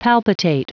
Prononciation du mot palpitate en anglais (fichier audio)
Prononciation du mot : palpitate